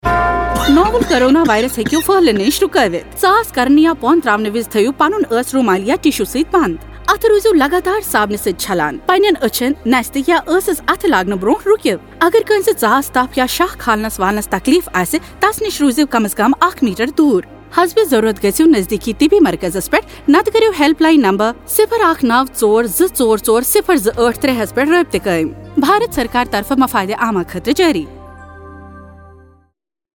Radio spot on key behaviours COVID-19_Kashmir_Kashmiri
5150_Cough Radio_Kashmiri.mp3